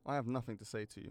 Voice Lines / Dismissive